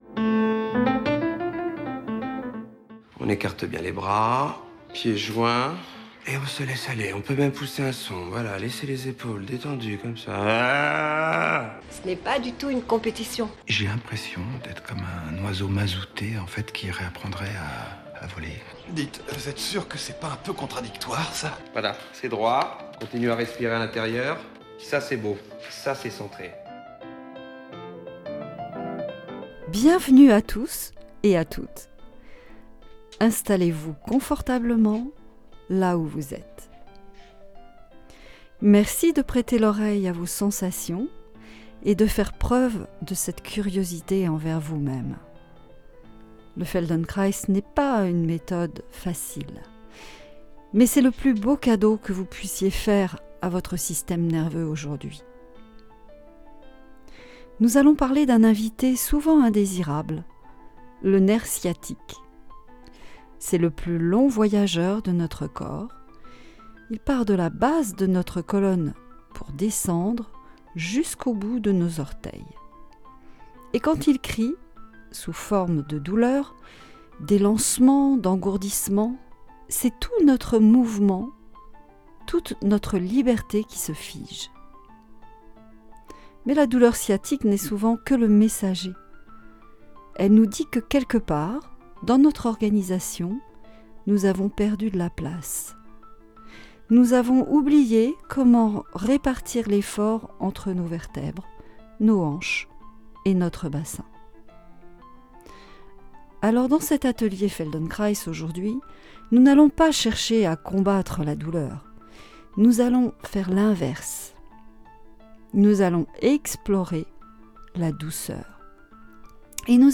Laissez-vous guider, sans effort et profitez de l'instant et de cette découverte de votre corps, de vos postures et de vos gestes. À l'approche du printemps, on soigne son nerf sciatique.